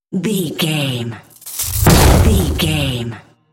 Dramatic whoosh to hit trailer
Sound Effects
Fast paced
In-crescendo
Atonal
dark
intense
tension
woosh to hit